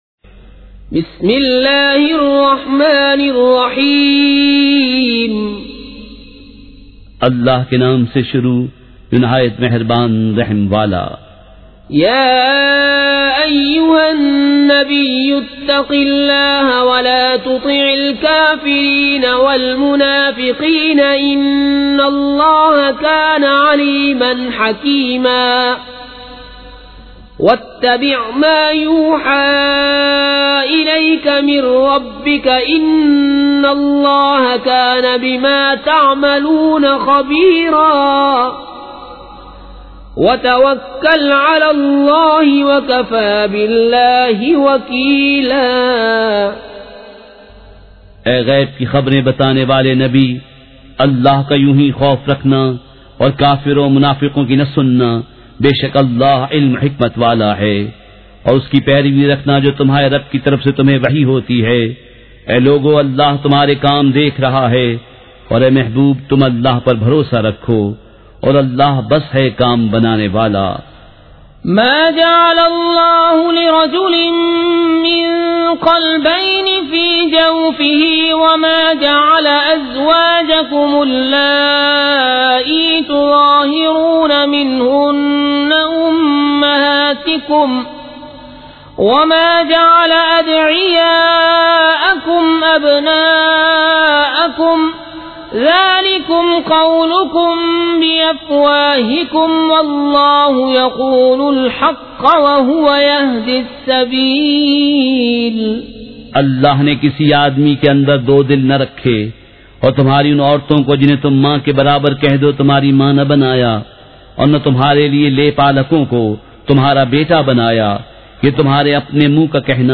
سورۃ الاحزاب مع ترجمہ کنزالایمان ZiaeTaiba Audio میڈیا کی معلومات نام سورۃ الاحزاب مع ترجمہ کنزالایمان موضوع تلاوت آواز دیگر زبان عربی کل نتائج 1871 قسم آڈیو ڈاؤن لوڈ MP 3 ڈاؤن لوڈ MP 4 متعلقہ تجویزوآراء